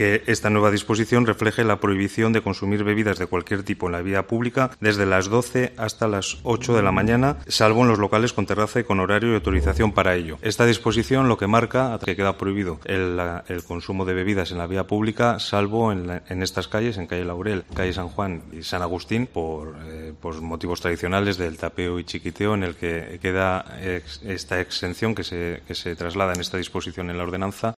Íñigo López Araquistain, concejal de Patrimonio del Ayuntamiento de Logroño